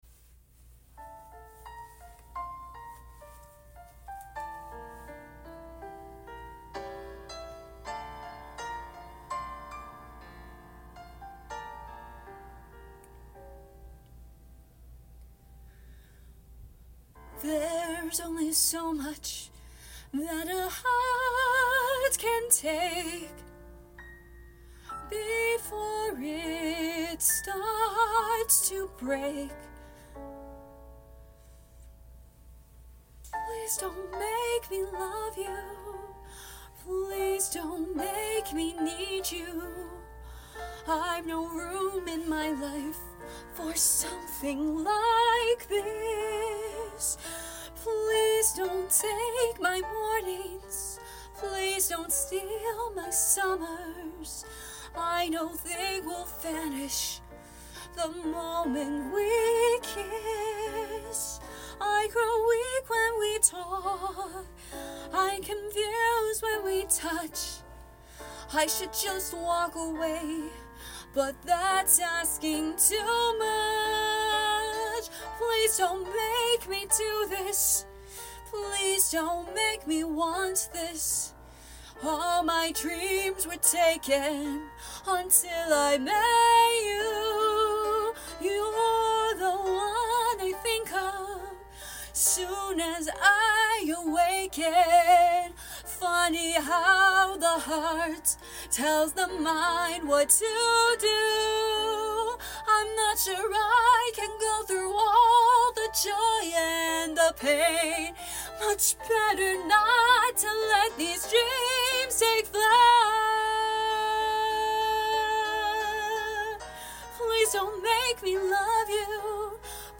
I am a soprano with a few alto tricks up my sleeve (F3-F6/G6)